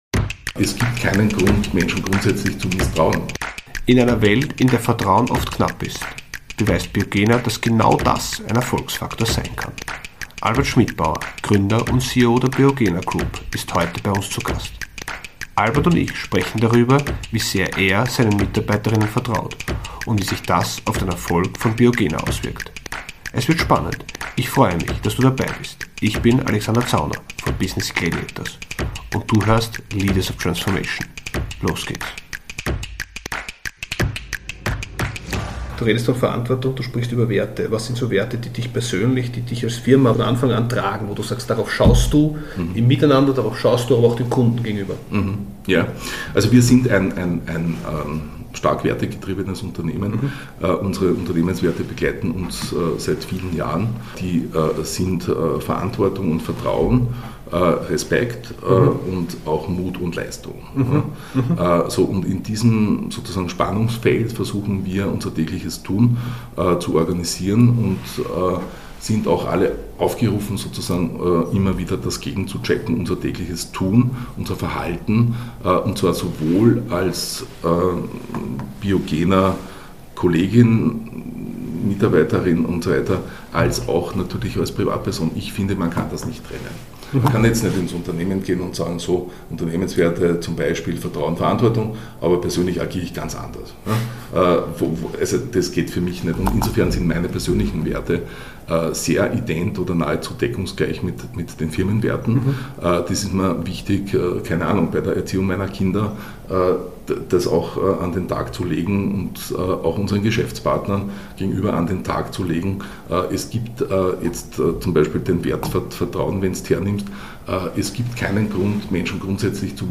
bei faszinierenden Gesprächen mit herausragenden Unternehmer:innen, Geschäftsführer:innen und Führungskräften. Es dreht sich alles darum, Transformationen wirkungsvoll zu gestalten und die volle Kraft von Unternehmen zu entfalten. In jeder Episode tauchen Entscheider:innen aus den unterschiedlichsten Branchen in die Tiefen ihrer Unternehmen ein.